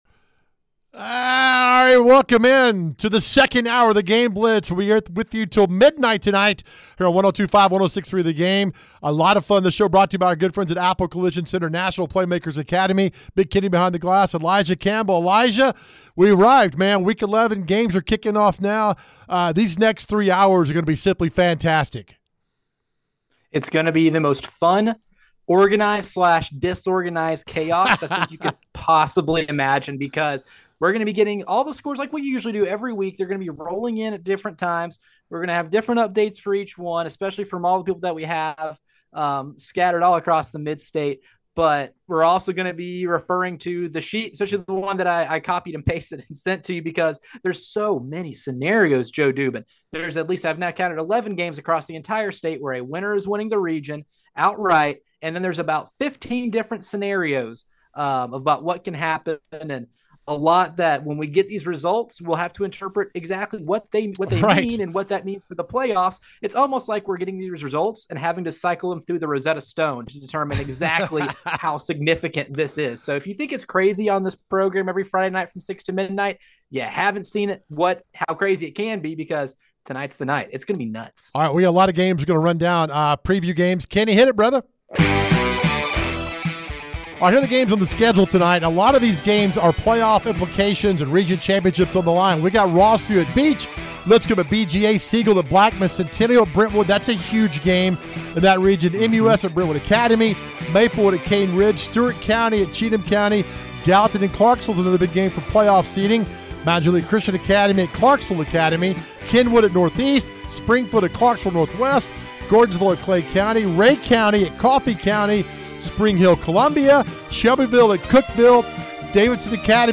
We have reporters on location as well as coaches interviews and scoreboard updates.